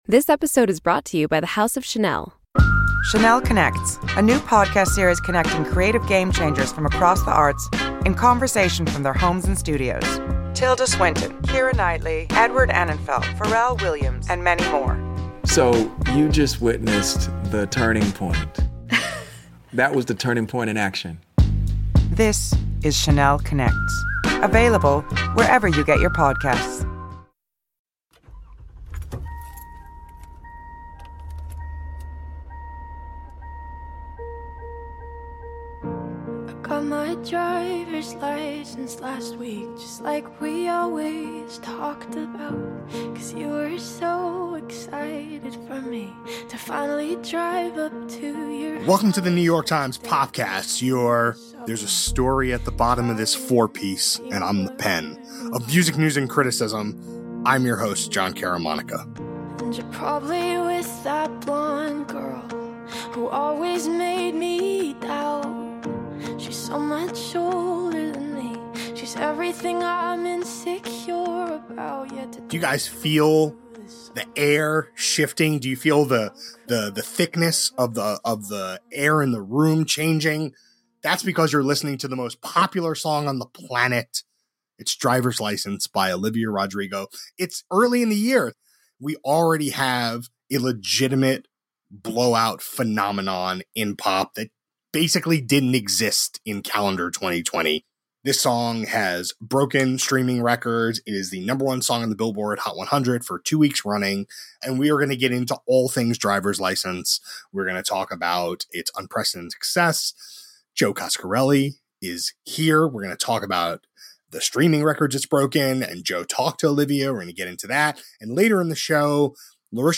A conversation about the year’s first genuine pop phenomenon, and the long arc of the Disney machine.